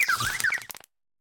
Cri de Têtampoule dans Pokémon Écarlate et Violet.
Cri_0938_EV.ogg